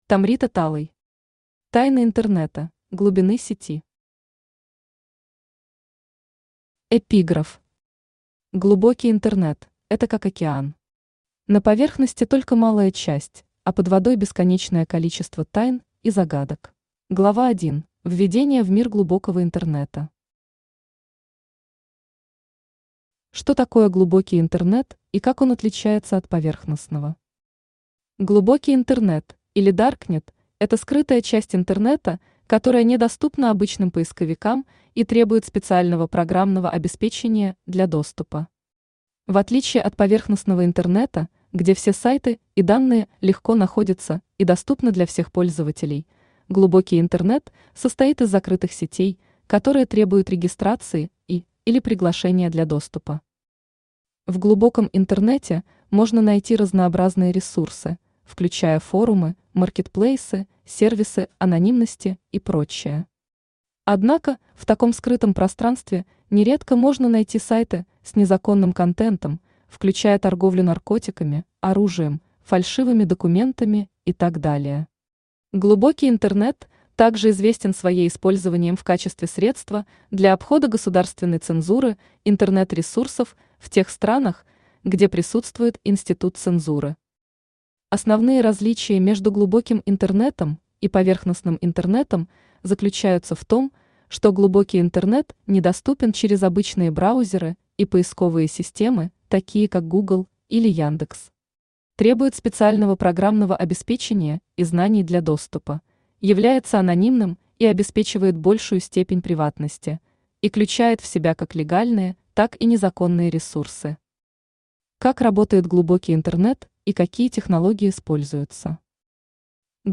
Аудиокнига Тайны интернета: Глубины сети | Библиотека аудиокниг
Aудиокнига Тайны интернета: Глубины сети Автор Tomrita Talay Читает аудиокнигу Авточтец ЛитРес.